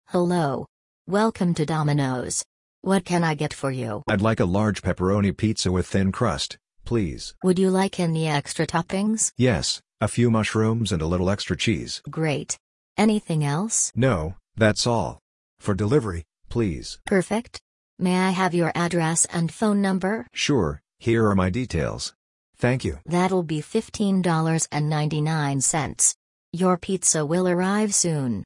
Conversation-at-Domino´s.mp3